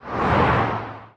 car1.ogg